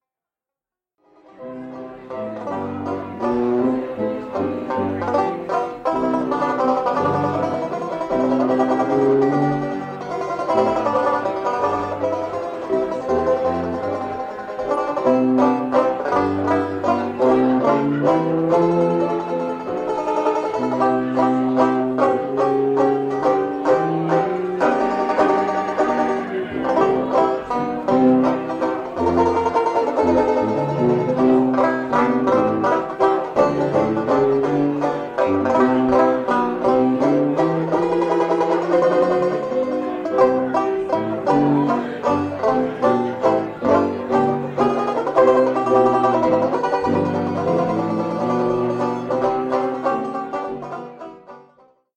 This song is in 3/4 waltz time.
Recorded at 39 Main Restaurant in Tiburon, CA - 1975